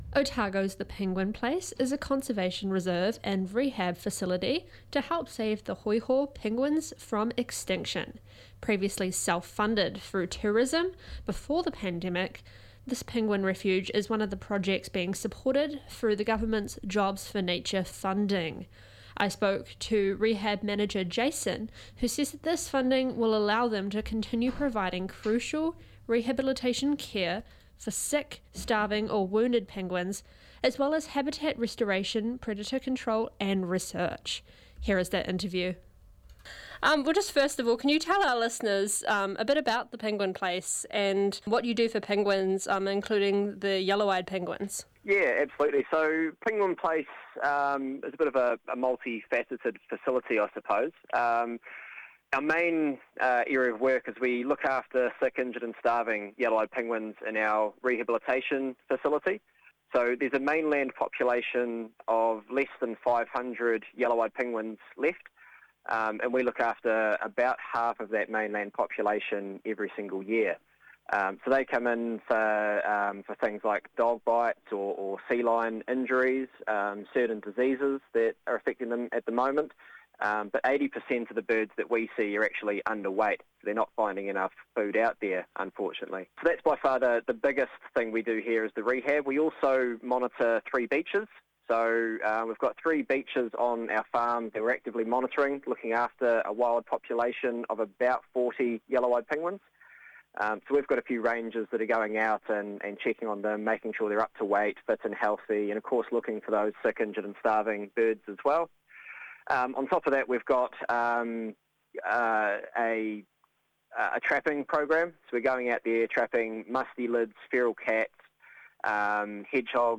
Hoiho Penguins jho.mp3